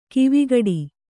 ♪ kivigaḍi